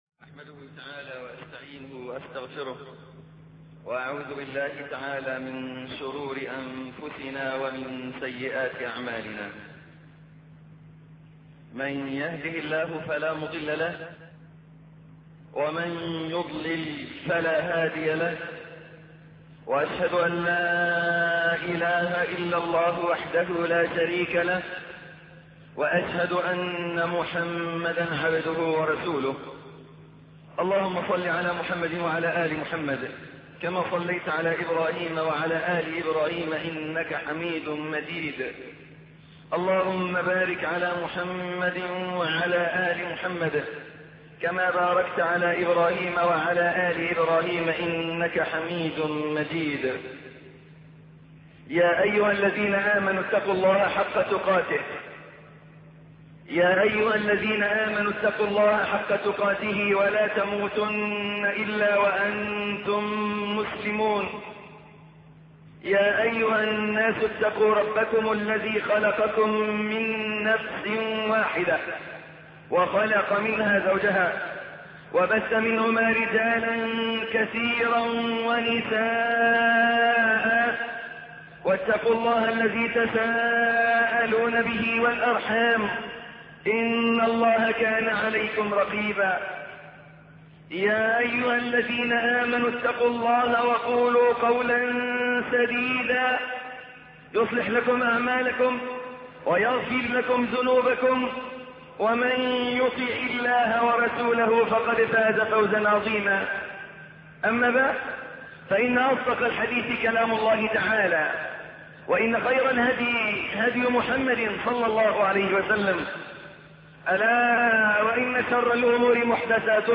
خطب ومحاضرات